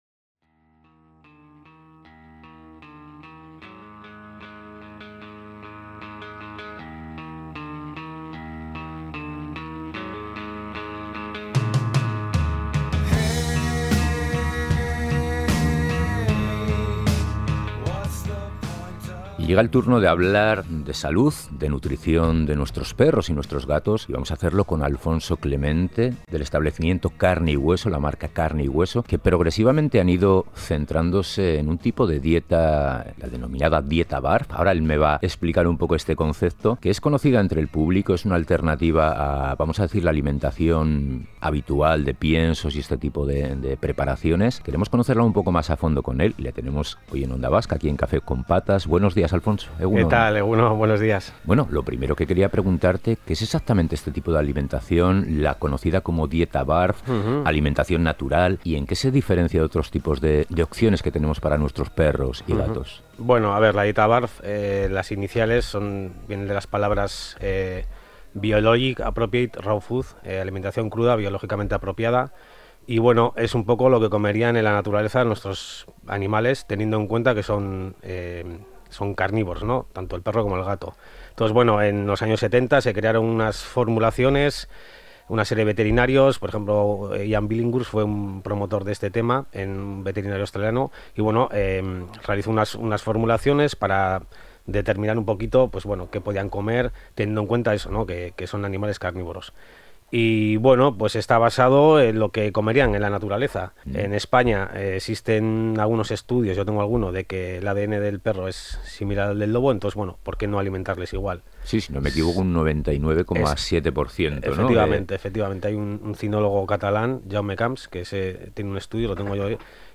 Escucha el podcast ¿En que consiste la dieta BARF? Conocemos sus beneficios para perros y gatos y disfruta con los mejores momentos de Café con Patas en su sección Entrevista...